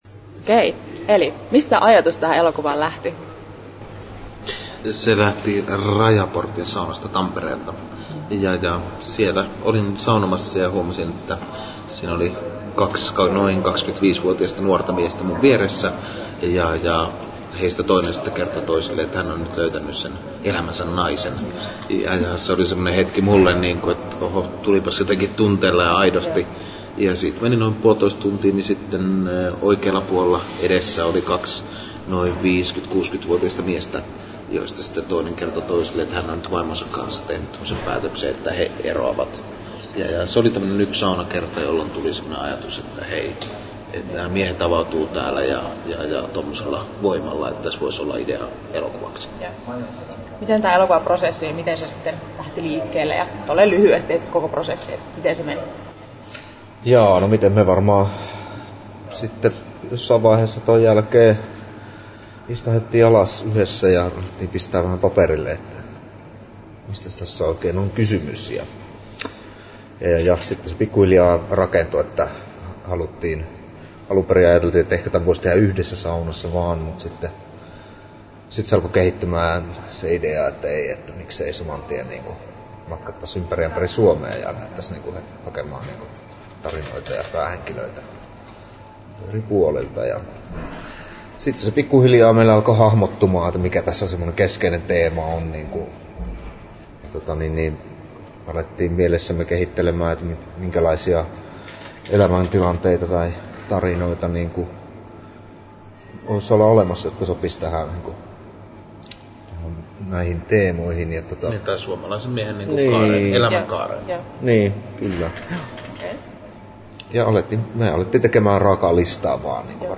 Haastattelut
7'16" Tallennettu: 23.03.2010, Turku Toimittaja